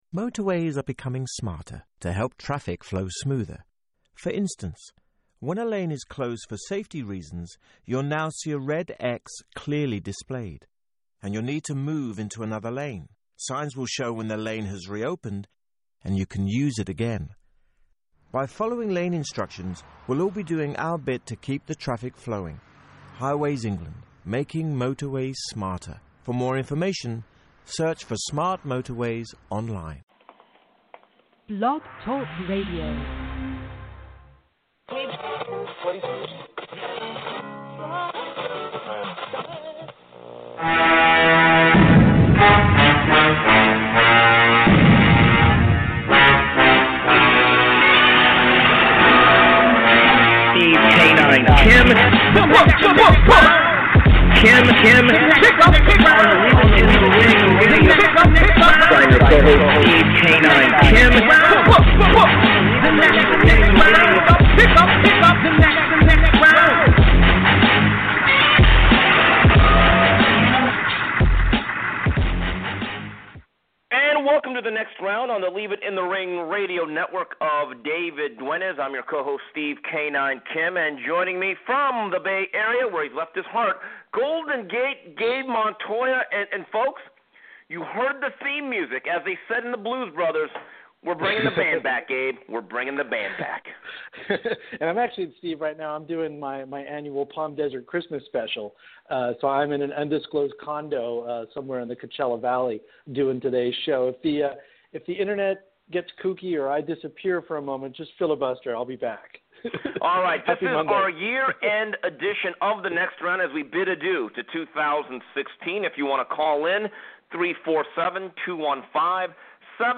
Plus, News & Notes and questions from callers and Twitter.